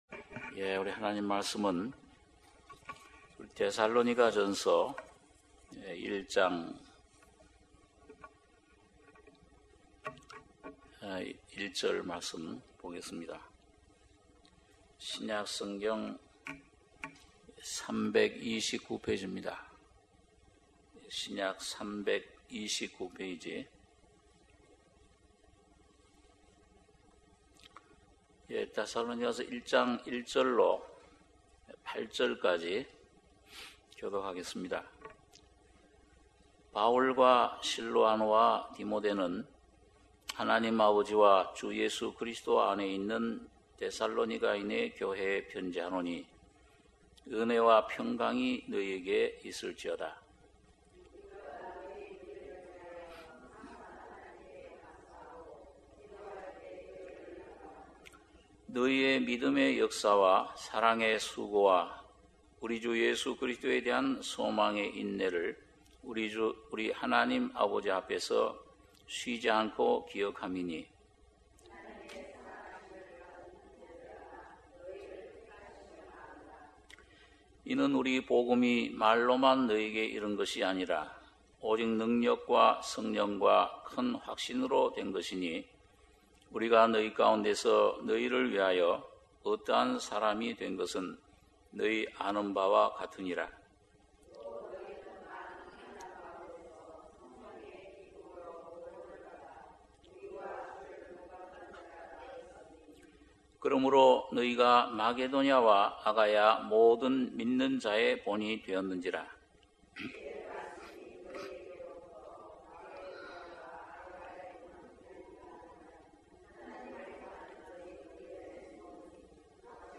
수요예배 - 데살로니가전서 1장 1절~8절